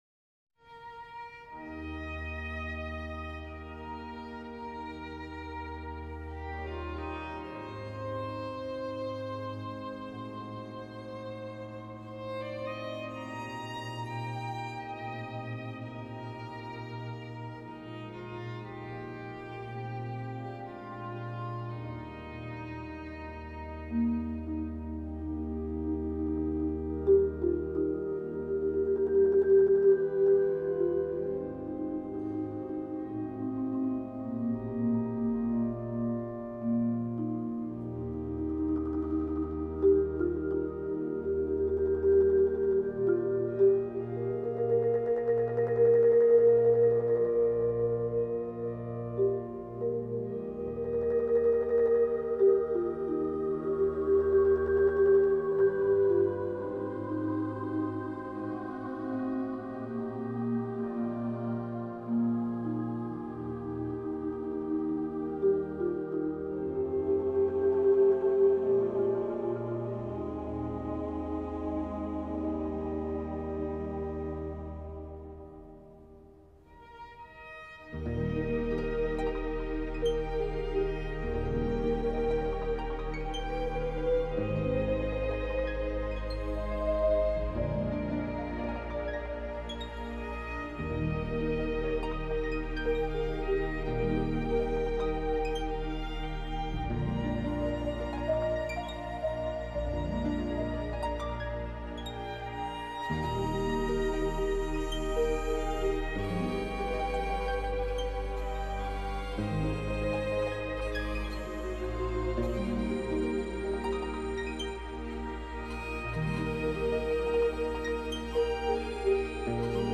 Amazing grace – violin / trompet mp3 :